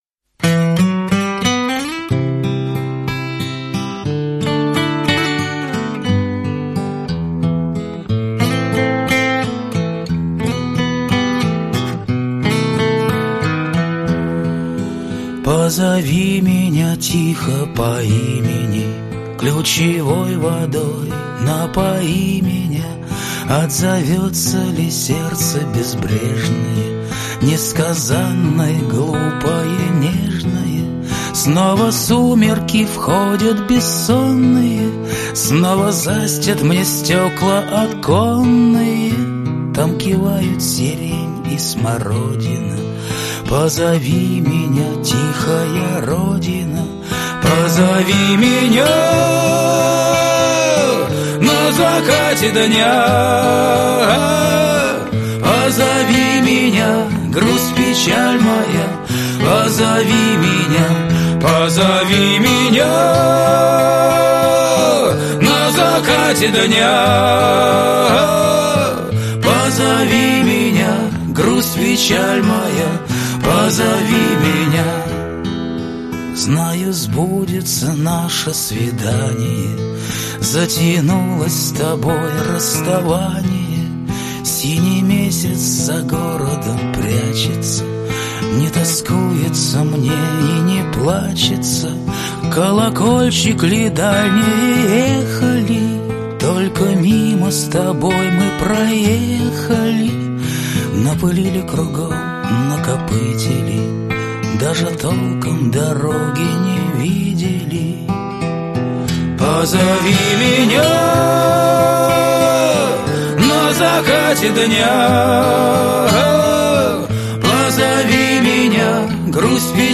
Музыка в финале сериала